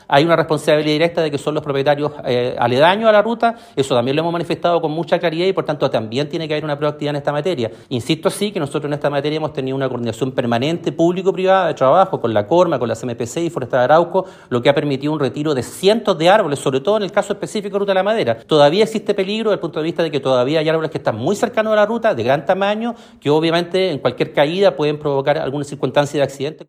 Tras lo ocurrido, el seremi de Obras Públicas, Hugo Cautivo, señaló que tiene que existir una preocupación permanente para retirar los árboles que ponen en peligro a quienes circulan por la ruta.